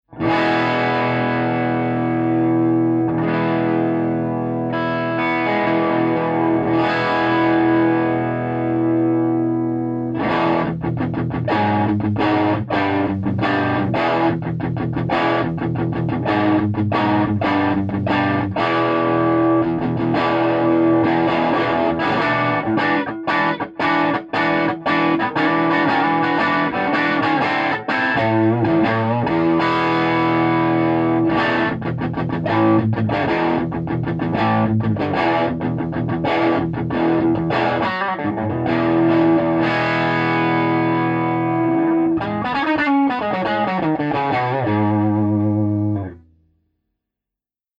AC30_ROOM
AC30_ROOM.mp3